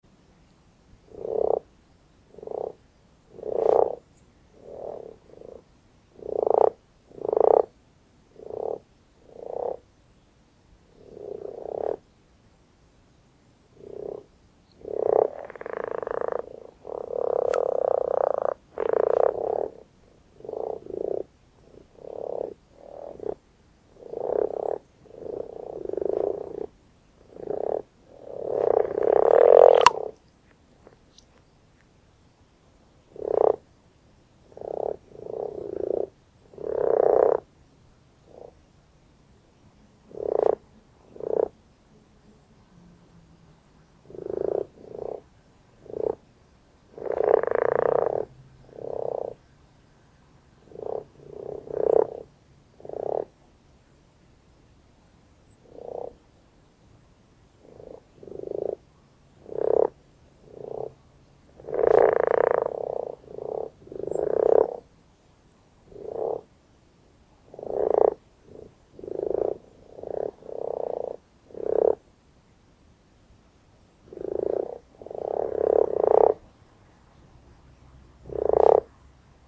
Audiodateien, nicht aus dem Schutzgebiet
Grasfrosch UB
rana temporaria grasfrosch.mp3